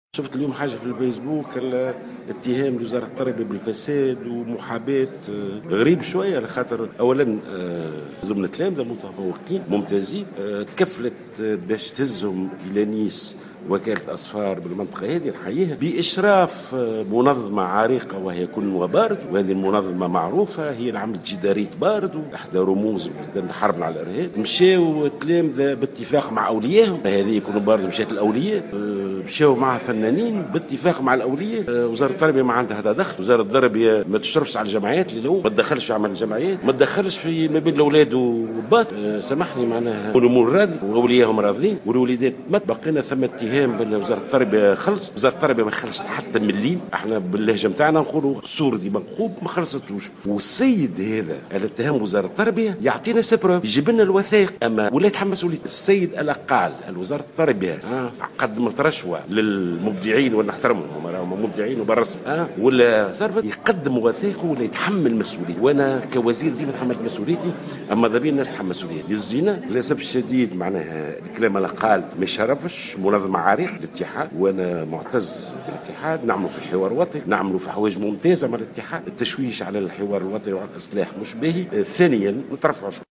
أوضح وزير التربية ناجي جلول خلال حضوره افتتاح مهرجان المنستير الدولي مساء اليوم...